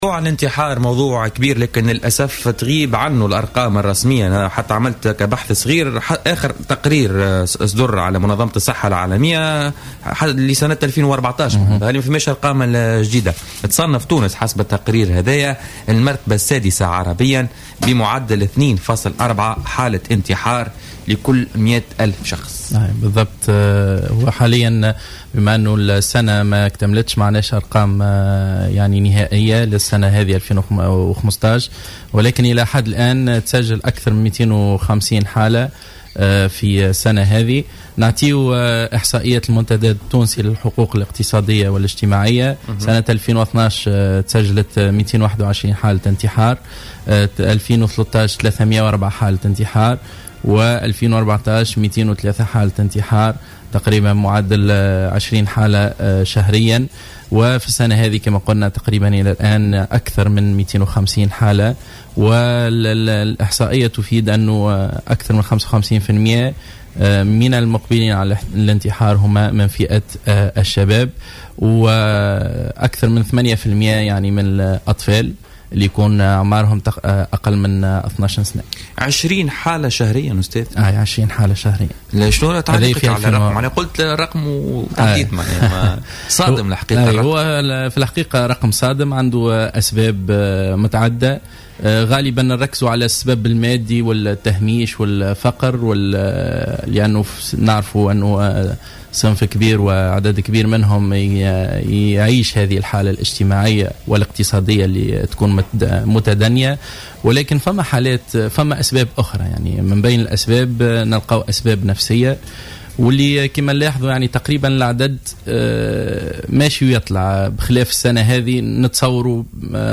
وأضاف في مداخلة له اليوم في برنامج "بوليتيكا" أن 55 بالمائة من المقبلين على الانتحار هم من الشباب مقابل أكثر من 8 بالمائة من الأطفال.